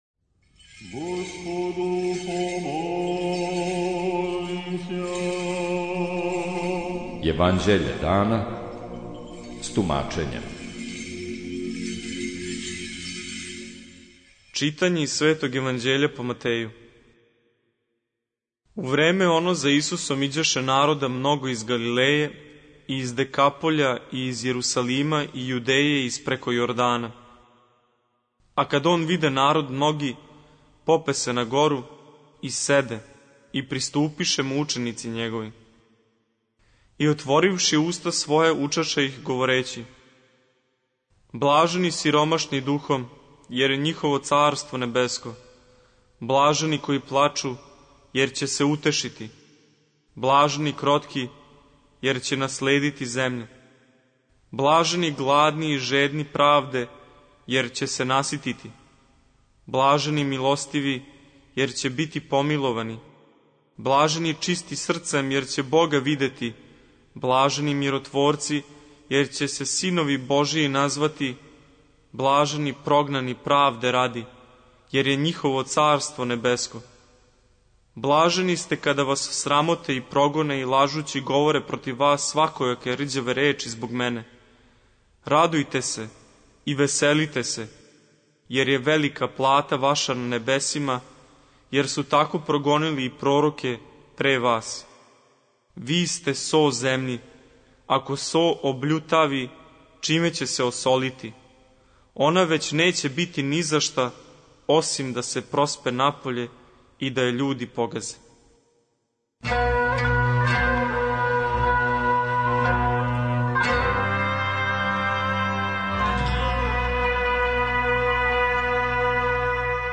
Читање Светог Јеванђеља по Марку за дан 07.02.2025. Зачало 58.